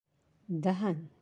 (dahan)